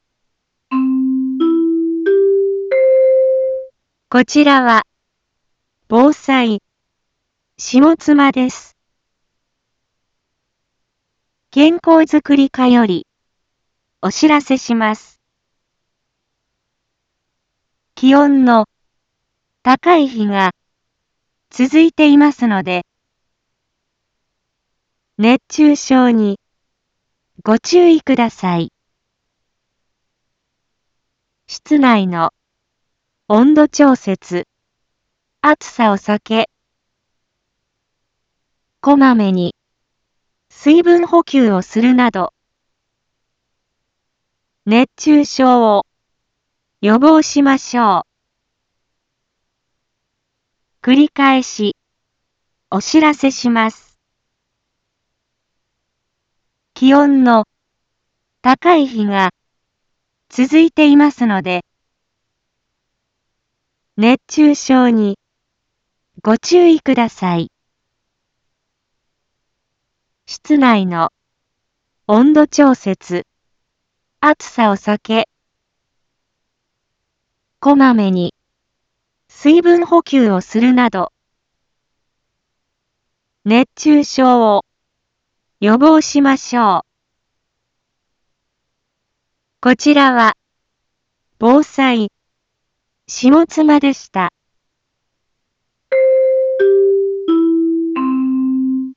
一般放送情報
Back Home 一般放送情報 音声放送 再生 一般放送情報 登録日時：2023-07-31 11:01:44 タイトル：熱中症注意のお知らせ インフォメーション：こちらは、防災、下妻です。